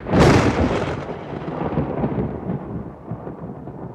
Kodiak Bear from Burger King Roar